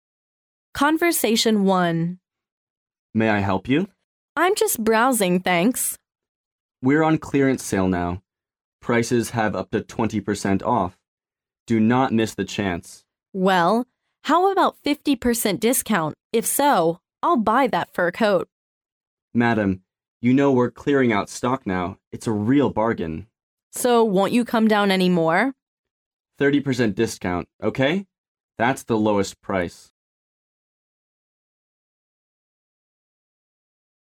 Conversation 1